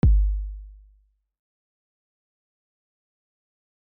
Step 1: Find an 808
Don’t worry about the pitch part yet; just find yourself a classic 808 kick sound without any distortion.